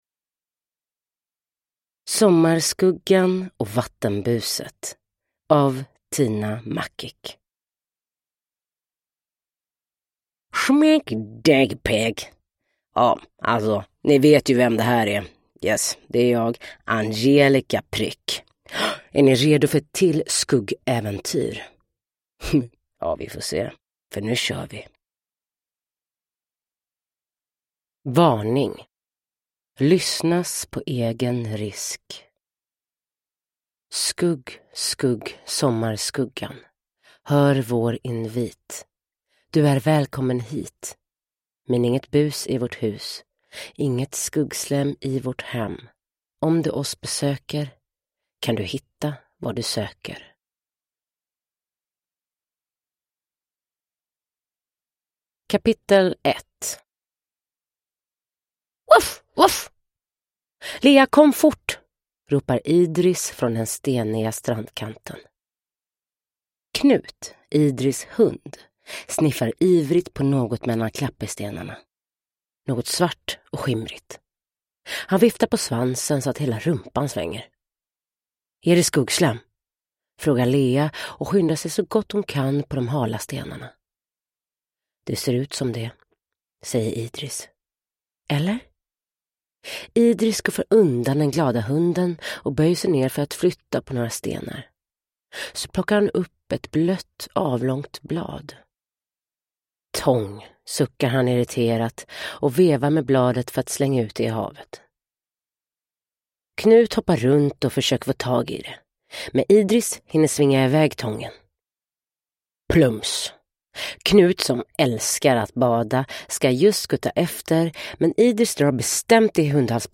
Sommarskuggan och vattenbuset – Ljudbok – Laddas ner